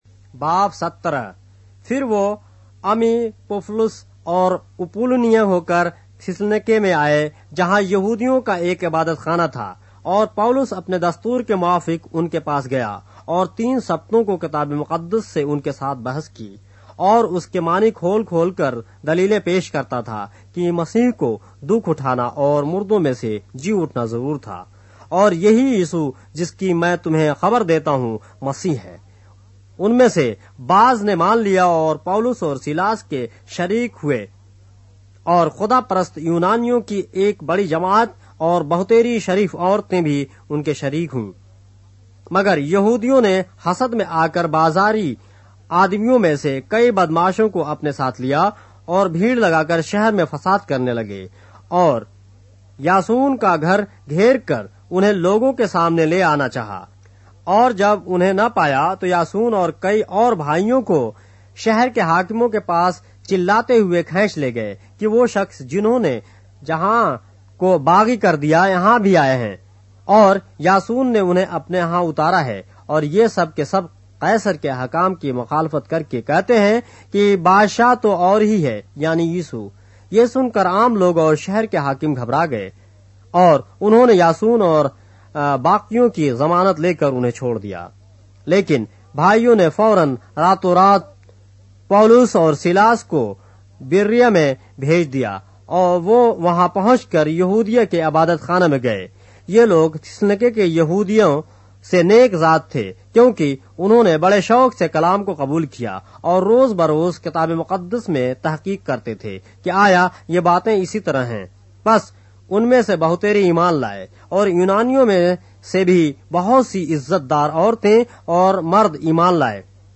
اردو بائبل کے باب - آڈیو روایت کے ساتھ - Acts, chapter 17 of the Holy Bible in Urdu